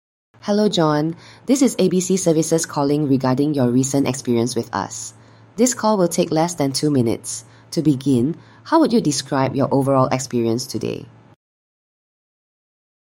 These are sample AI-driven voice interactions, adapted dynamically based on how customers respond
Singapore | English | Natural AI-led survey opening using a fictional business and customer name